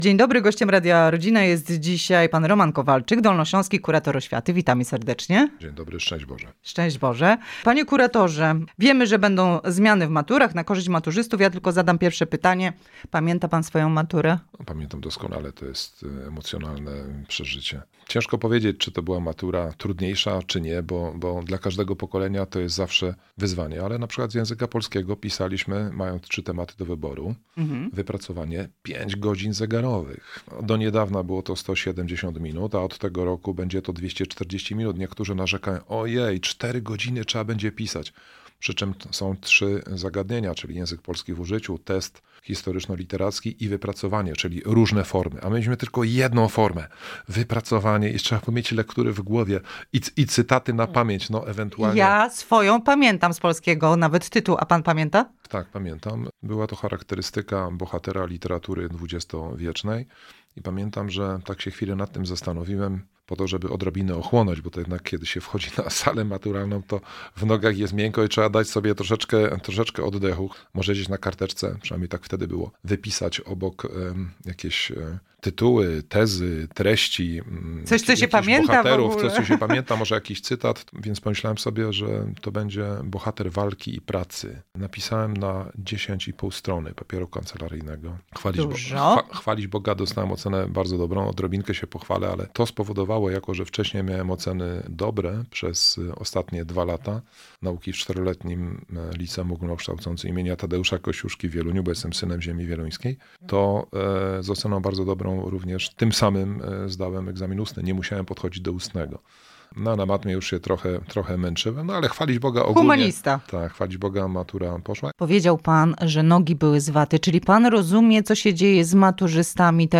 Matura jest do zdania, lektury można jeszcze nadrobić - zapewnia Dolnośląski Kurator Oświaty - Radio Rodzina
PG_kurator-oswiaty-Roman-Kowalczyk-o-maturach_KP-2.mp3